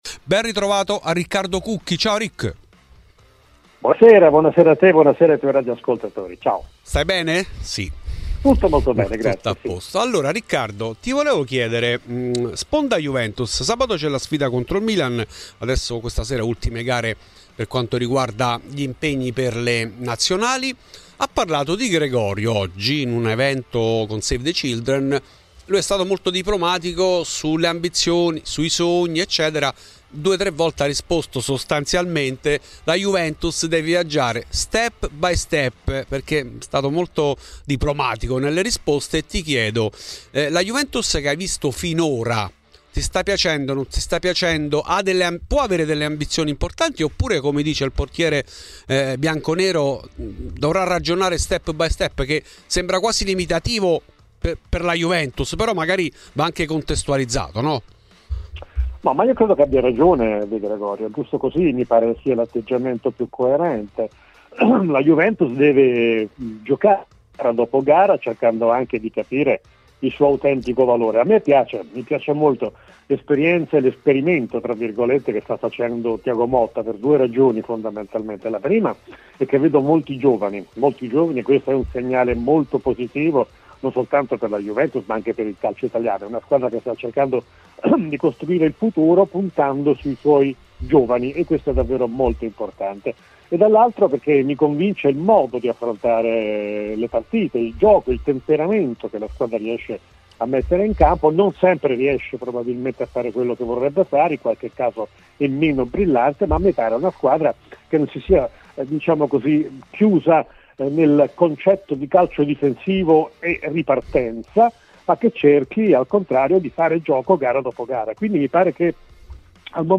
In ESCLUSIVA a Fuori di Juve Riccardo Cucchi , storica voce di Tutto il calcio minuto per minuto.